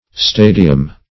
Stadium \Sta"di*um\ (st[=a]"d[i^]*[u^]m), n.; pl. Stadia